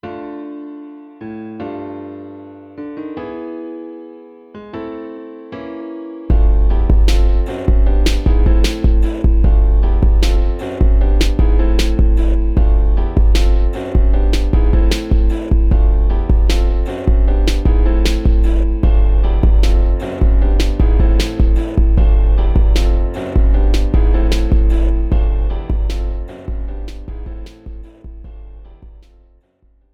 Professional Karaoke Backing Track.